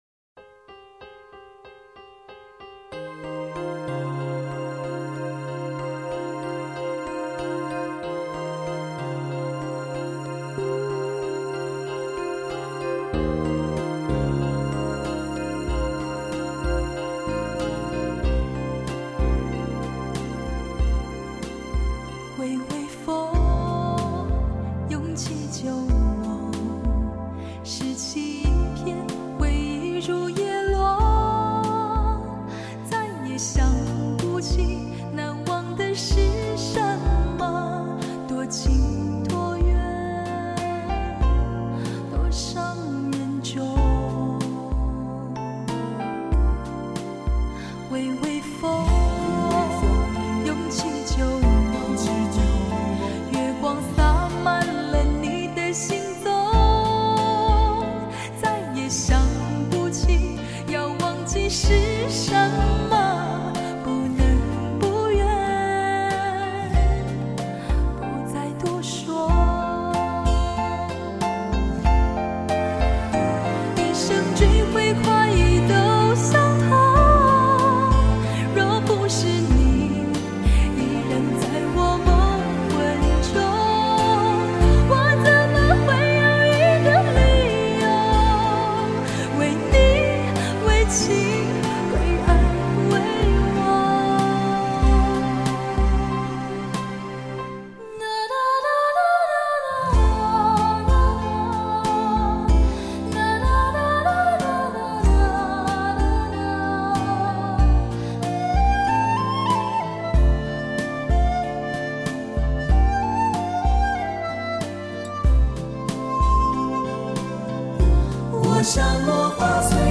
她的声音柔柔地熨过
最精采的生活情歌